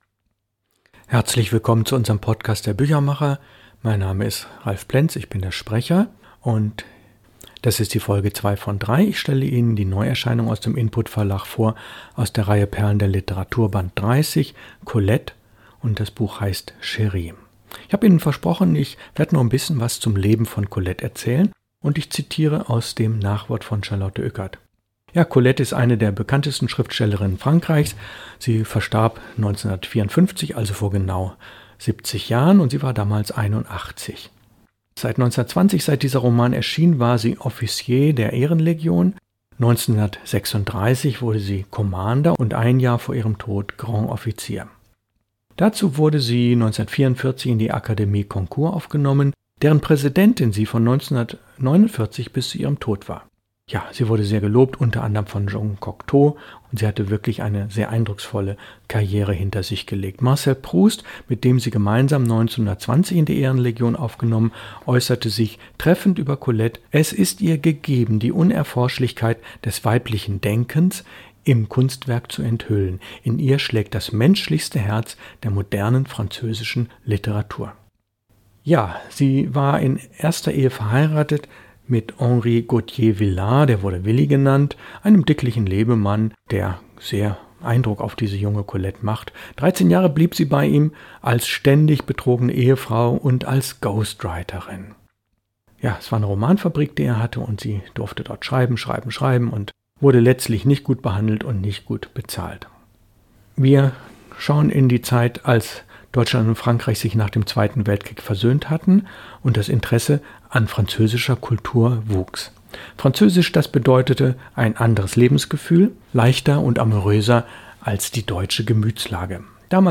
Lesung aus: Colette – Chéri, Folge 2 von 3